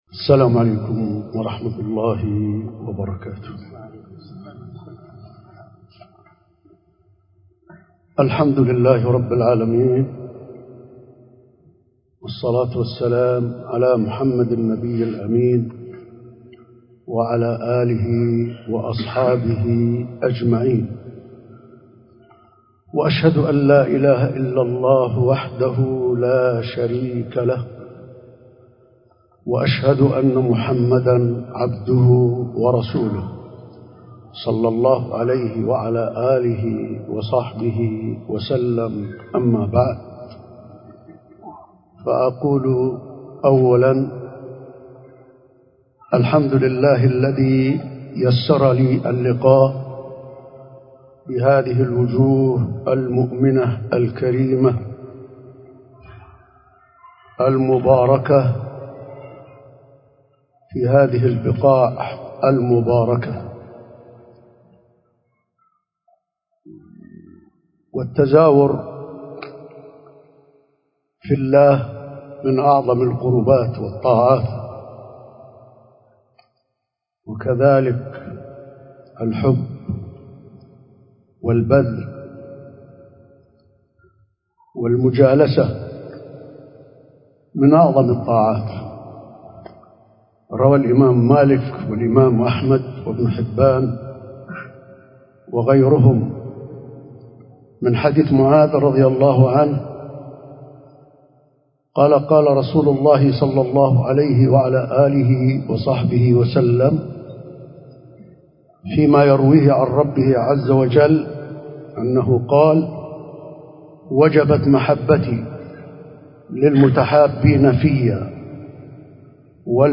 موعظة مؤثرة في مكة المكرمة بعنوان ﴿ رحلة للآخرة ﴾
ألقيت في مكة المكرمة